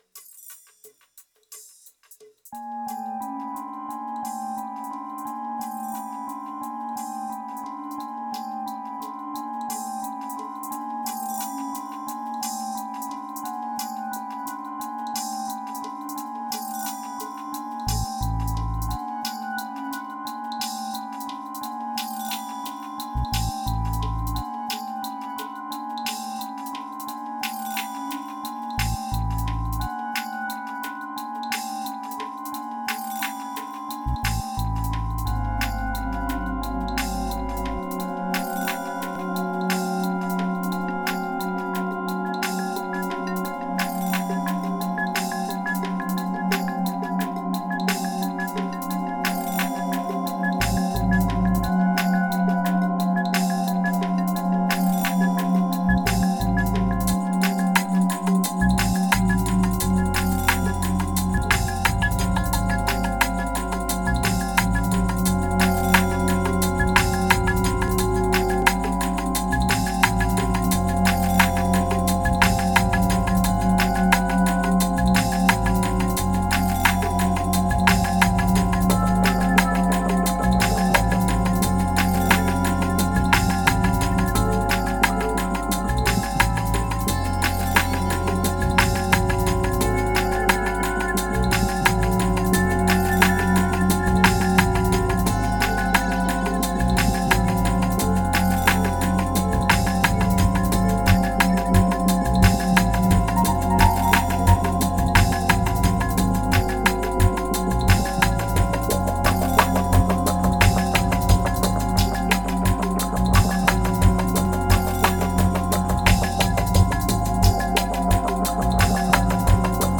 2141📈 - 32%🤔 - 88BPM🔊 - 2015-02-14📅 - -24🌟